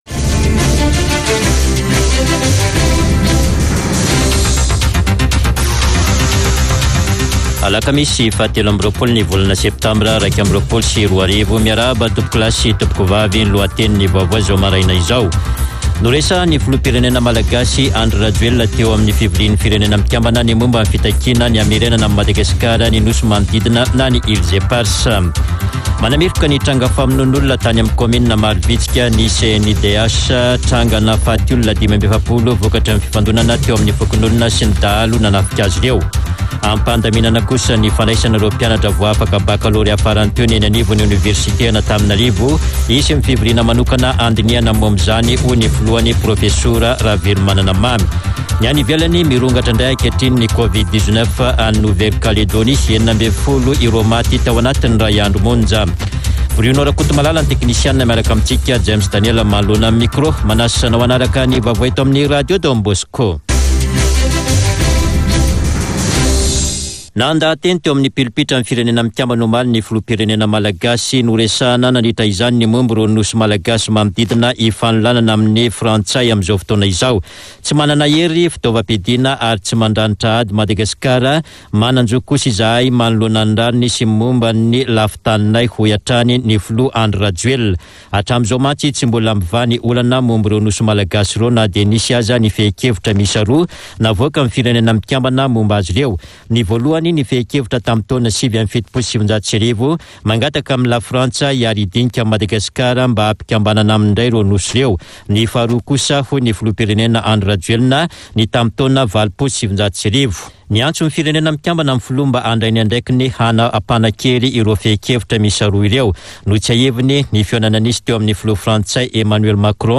[Vaovao maraina] Alakamisy 23 septambra 2021